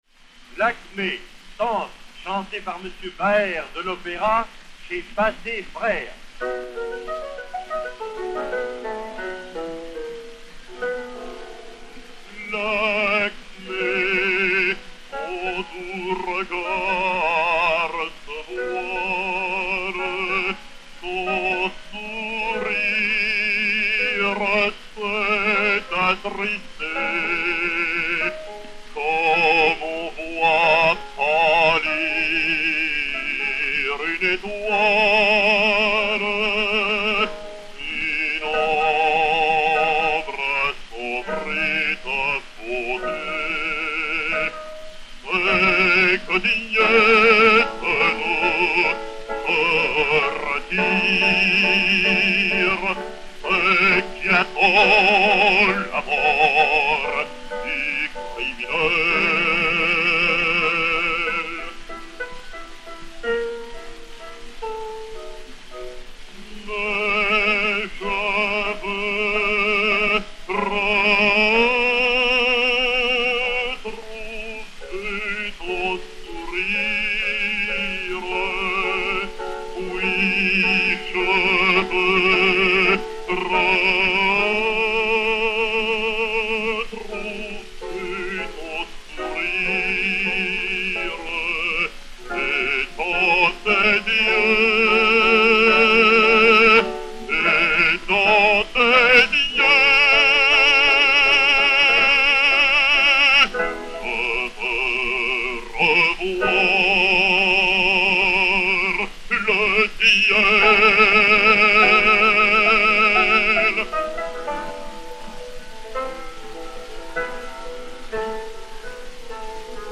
basse française
et Piano